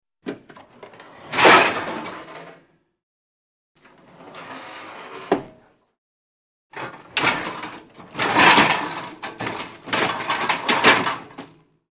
Dishwasher; Door 2; Dishwasher Open And Close With Dish Movement. Medium Close Perspective. Kitchen, Restaurant.